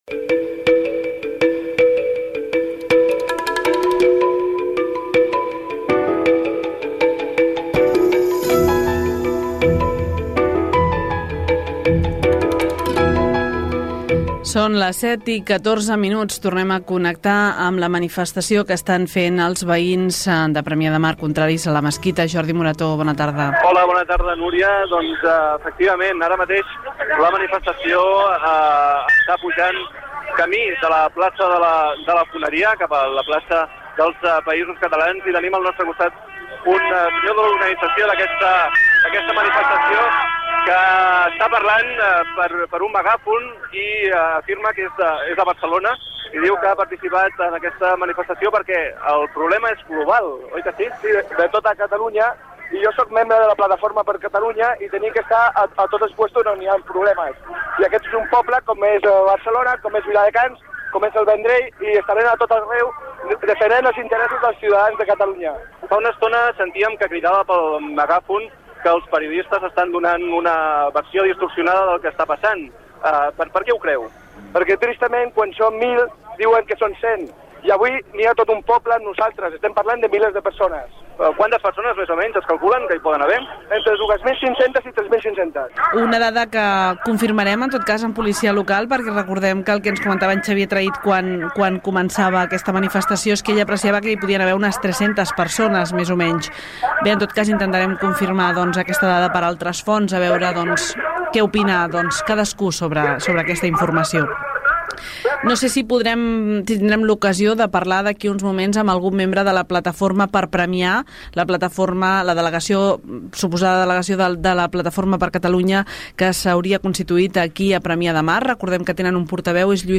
Hora, connexió amb la manifestació de veïns de Premià de Mar en contra de la construcció d'una mesquita a la localitat.
Indicatiu, connexió amb la seu de la Policía Local per conèixer el nombre de participants, valoració del tinent d'alcaldia de Premià Tomàs Esteban
Informatiu